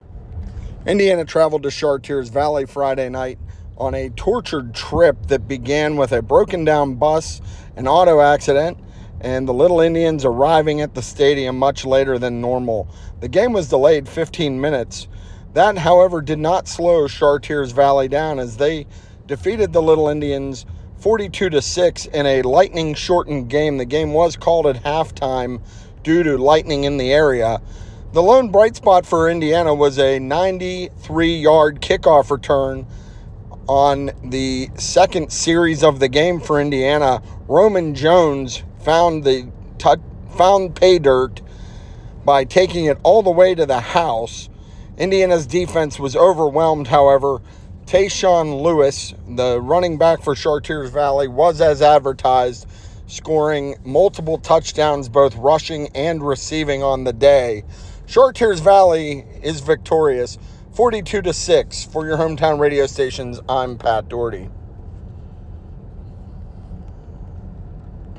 9-6-25-indiana-char-valley-short-recap.wav